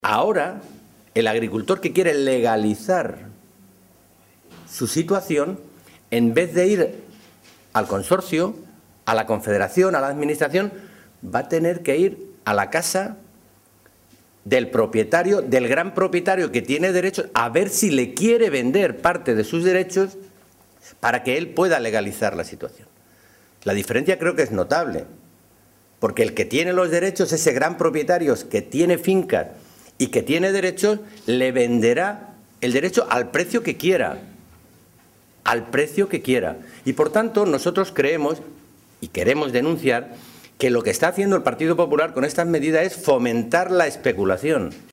Cortes de audio de la rueda de prensa